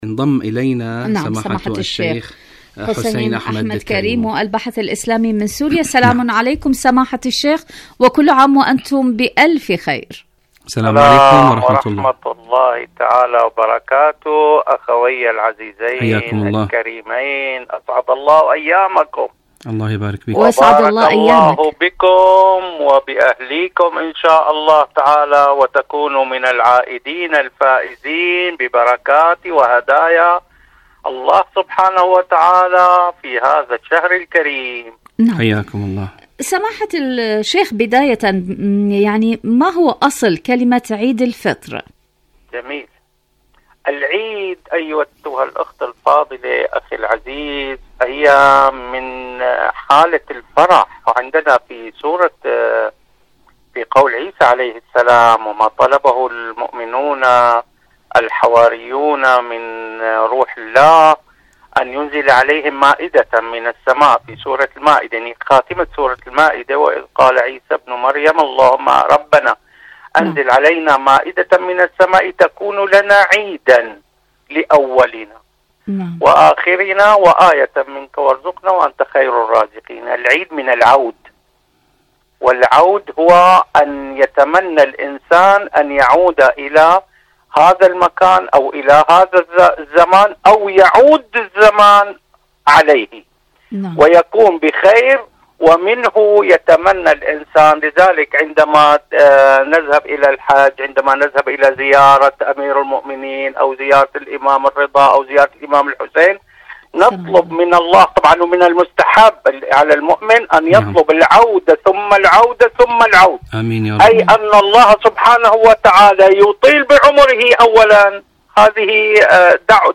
إذاعة طهران- ربيع الصائمين: مقابلة إذاعية